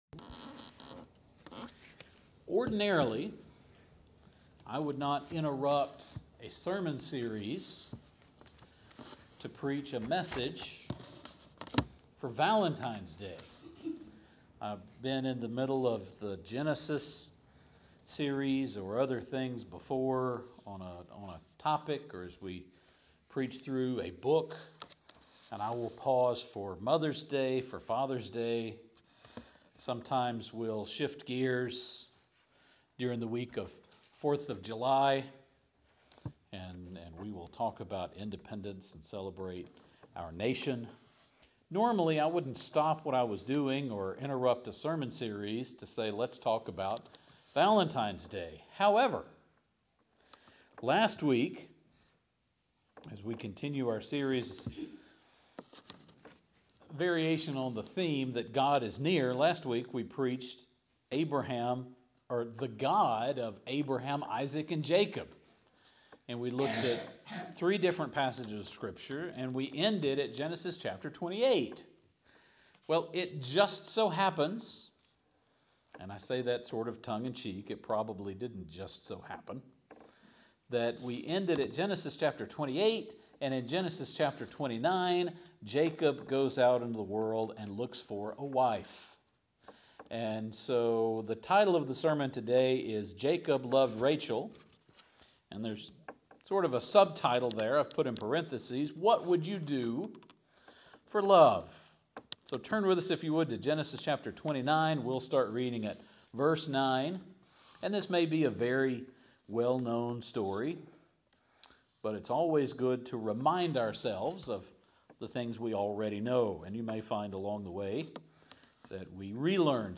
I would not ordinarily interrupt a sermon series to do anything for Valentine’s Day.